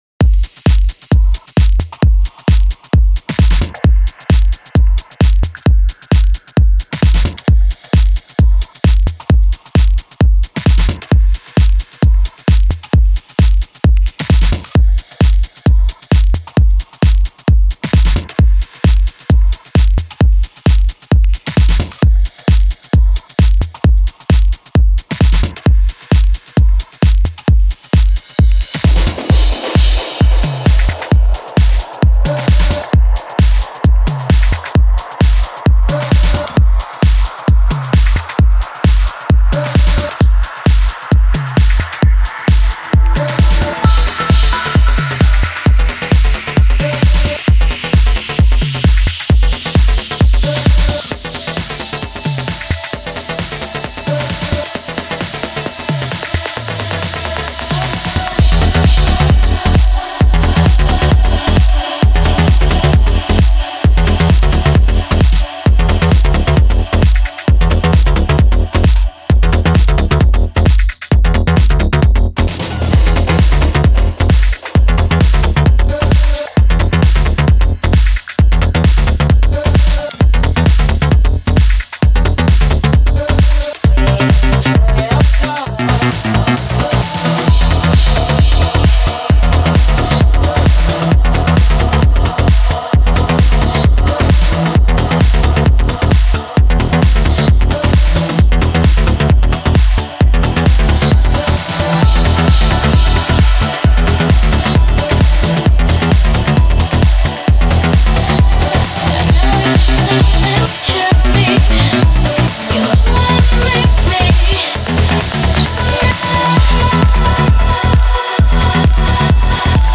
Жанр: Trance | Progressive